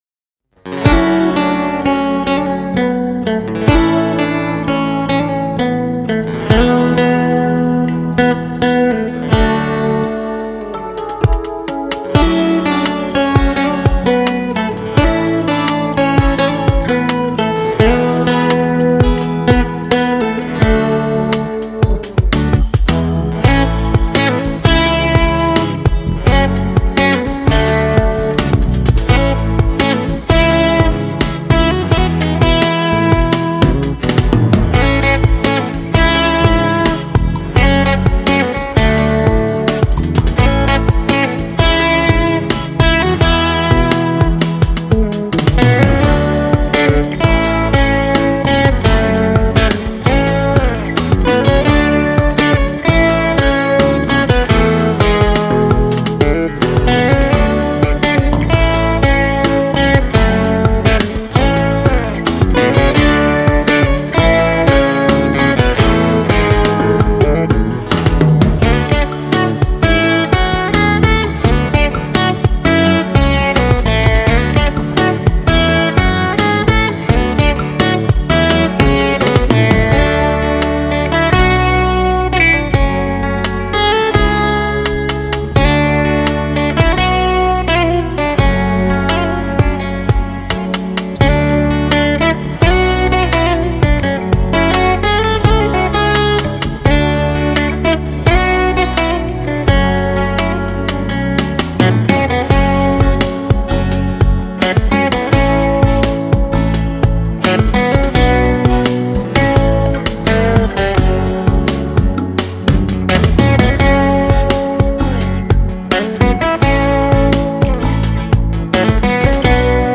• گیتار
• گیتار باس